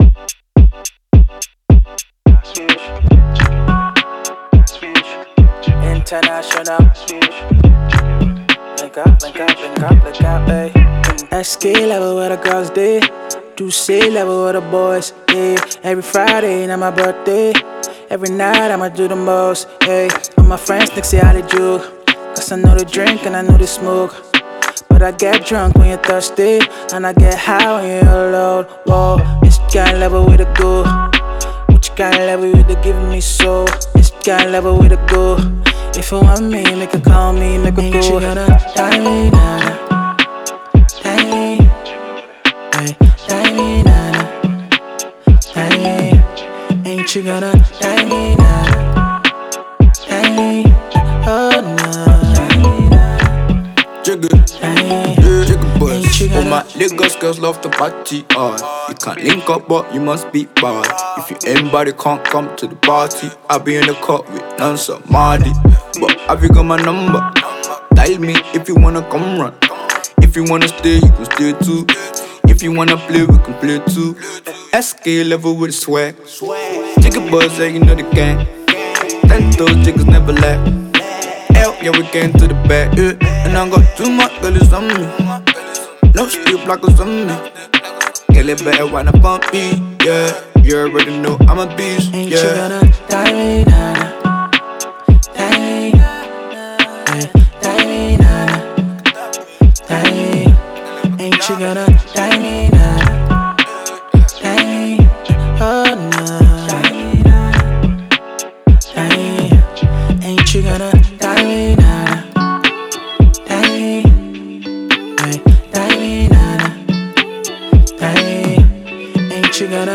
smooth single
a potential party starter